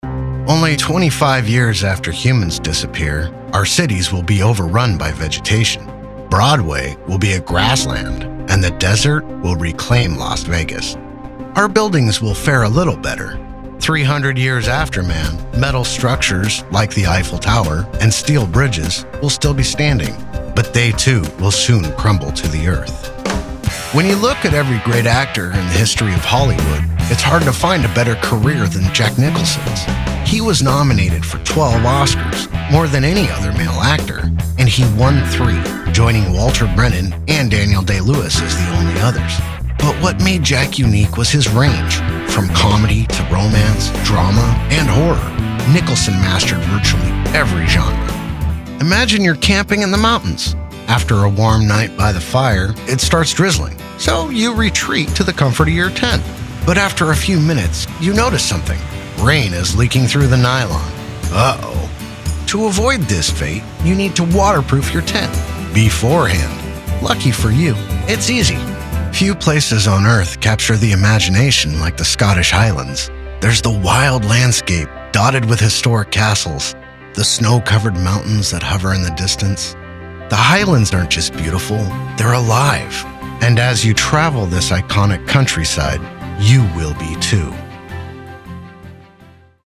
Narration Demo
Middle Aged
I have a broadcast quality home studio and love connecting for directed sessions.